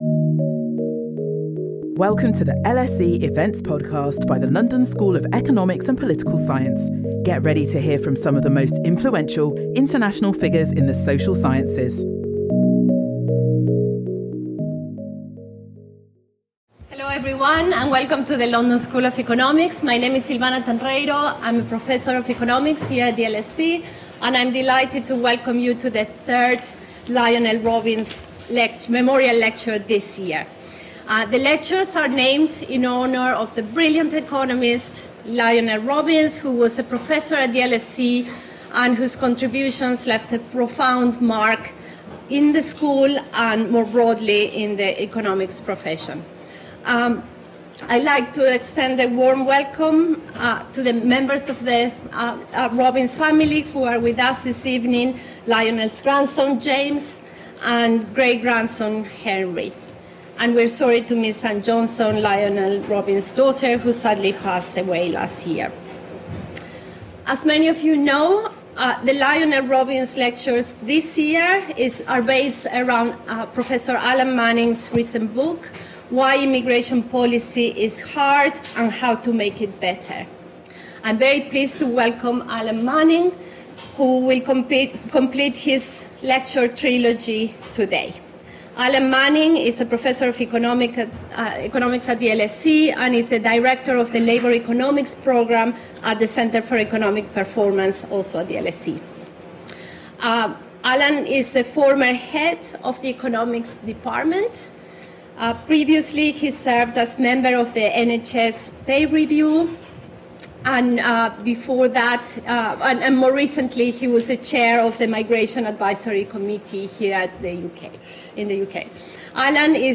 Join us for the 2026 Lionel Robbins Memorial Lectures which this year will be delivered by Alan Manning.